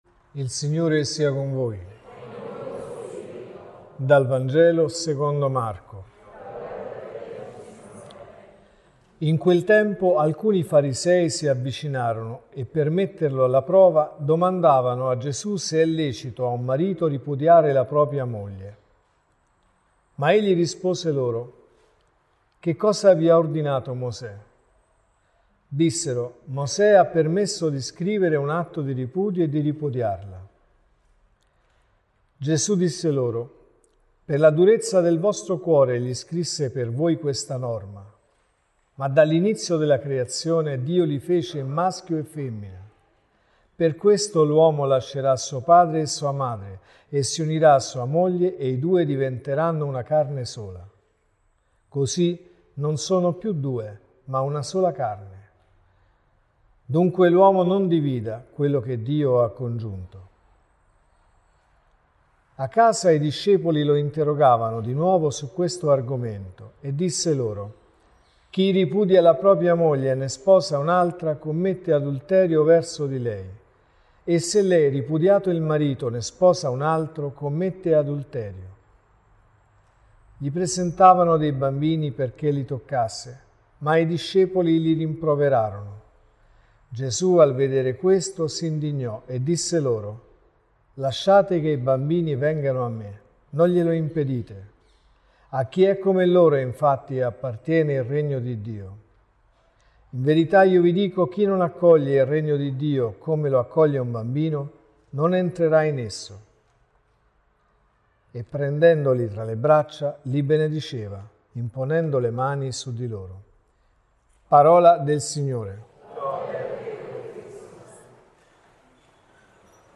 L’uomo non divida quello che Dio ha congiunto. (Messa del mattino e della sera)